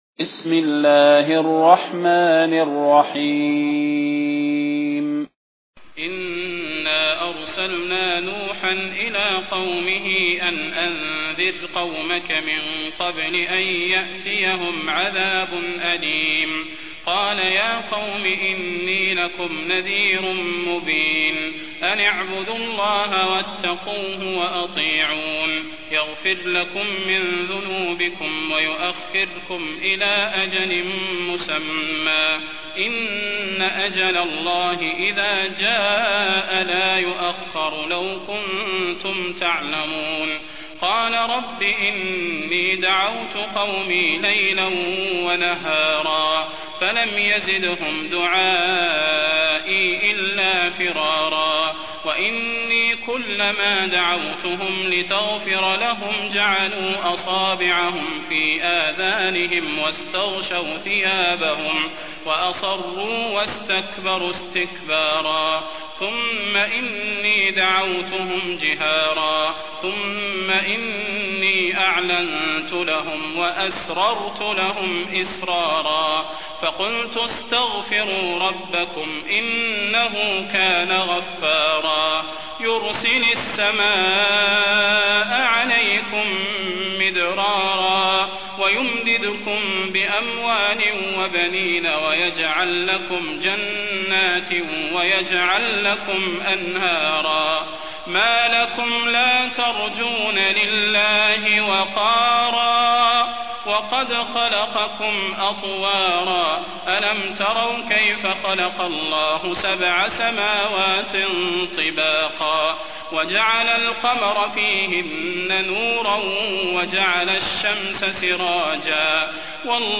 المكان: المسجد النبوي الشيخ: فضيلة الشيخ د. صلاح بن محمد البدير فضيلة الشيخ د. صلاح بن محمد البدير نوح